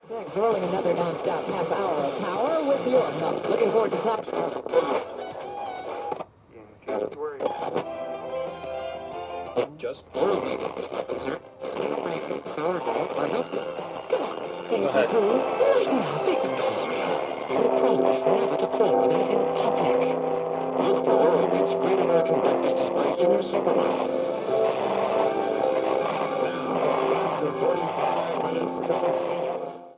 Radio Tuning, Tuning Radio, Various Stations, Mostly Static & Voices.